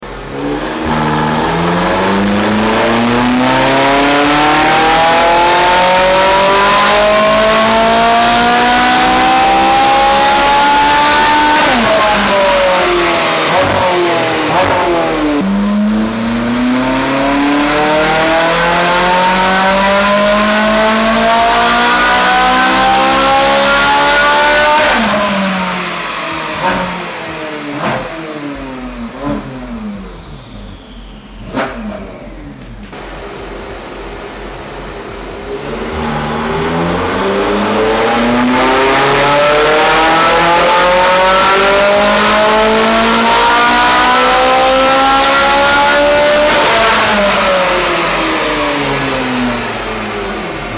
ENGINE SIZE 4.5L V8